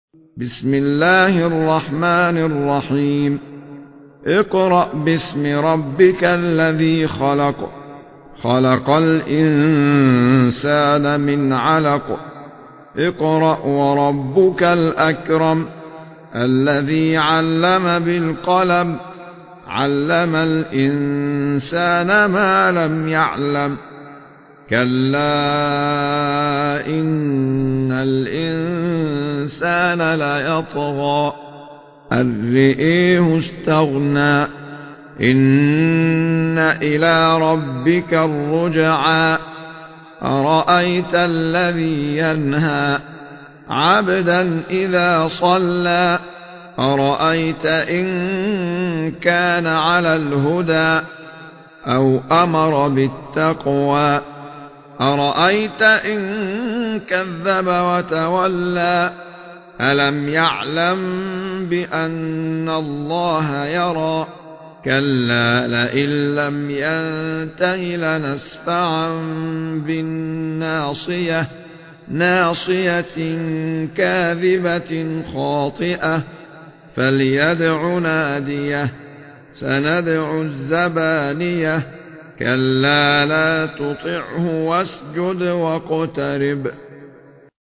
مصاحف - أحمد عيسى المعصراوي
المصحف المرتل - شعبة عن عاصم بن أبي النجود